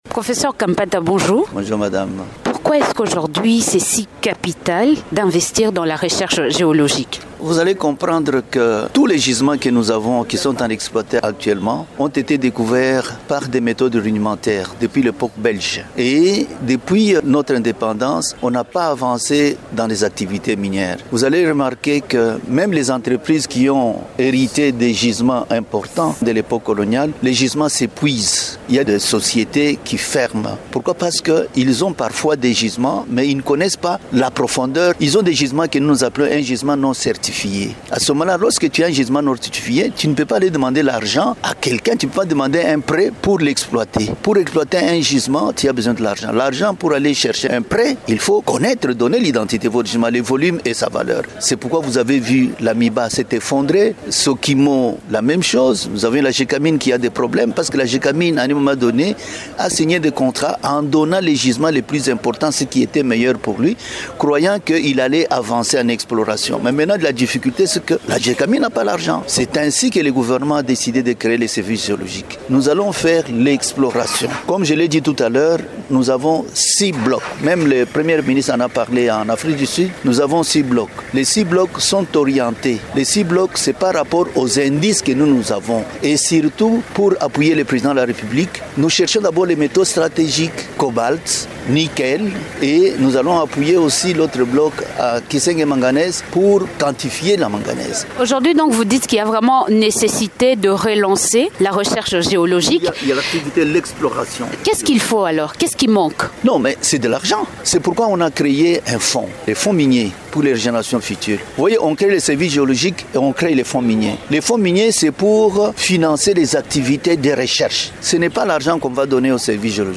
Invité de Radio Okapi, cet expert minier attribue cette situation au manque de gisements certifiés qui permettent à une entreprise de trouver les moyens d’exploitation.